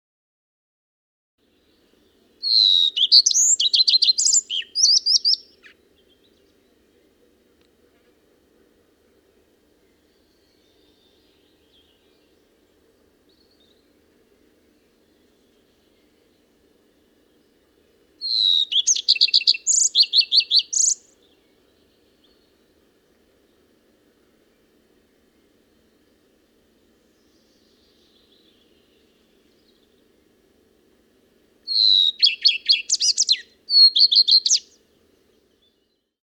Kızılkuyruk Kuşunun Ötüşü Nasıldır?
Kızılkuyruk kuşunun ötüşü, kısa ve melodiktir.
“sie-truii-truii-sii-sii-siiue” şeklindedir. İlk notası daha tizdir. Sıkça diğer kuşları taklit eder.
kizilkuyruk-kusu.mp3